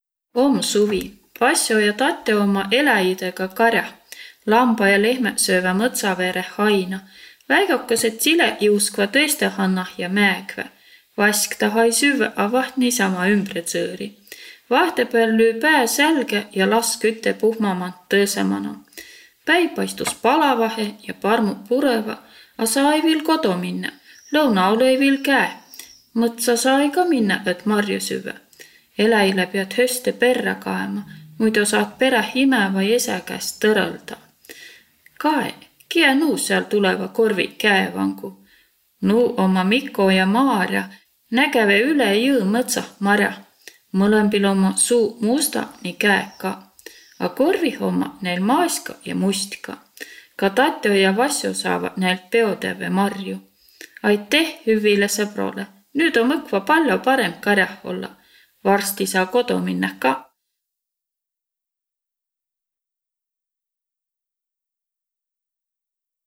Jutt “Om suvi. Vasso ja Tato omma’ kar´ah”
Peri plaadilt juttõ ja laulõ seto aabitsa mano.